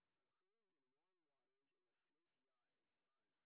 sp24_street_snr20.wav